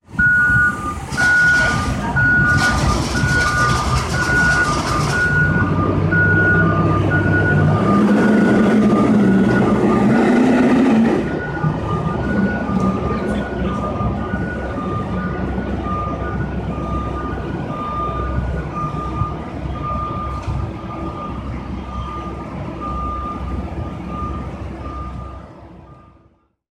Raw field recording of construction equipment on Montreal's McGill University campus.